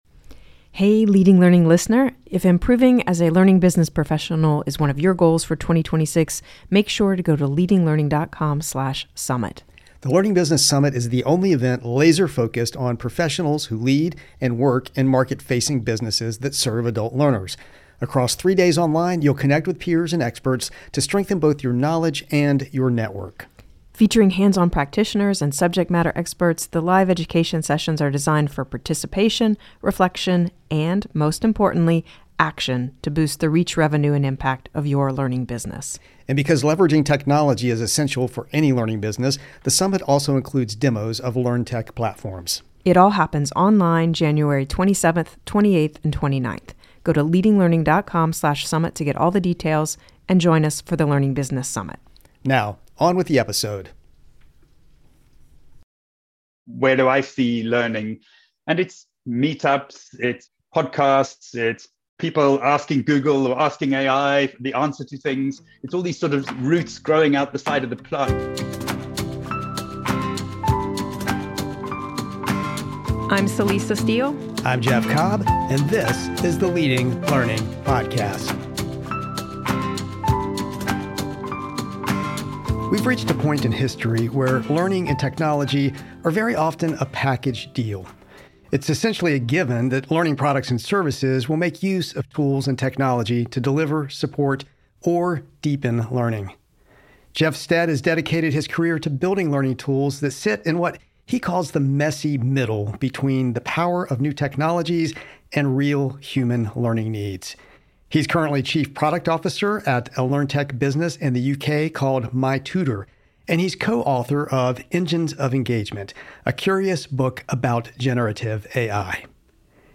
Leading Learning Podcast interviewee